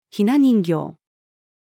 雛人形-female.mp3